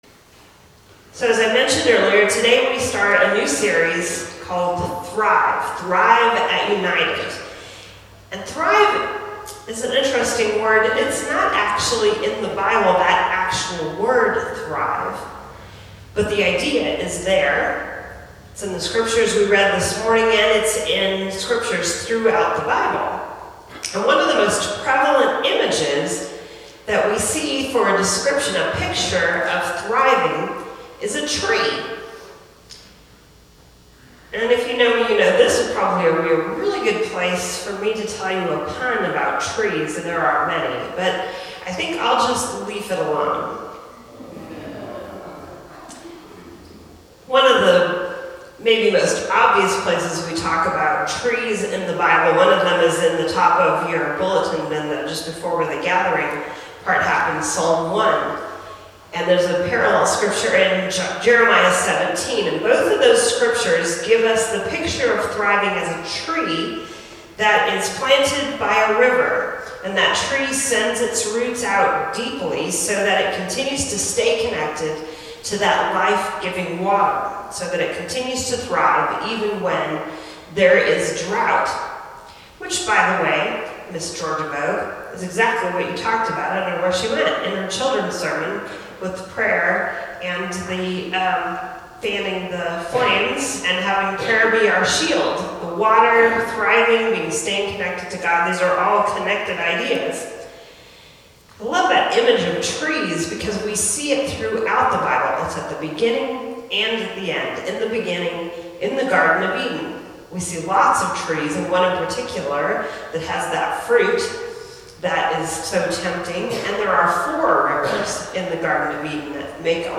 Choosing Life Over Death [April 15, 2018] – United Presbyterian Church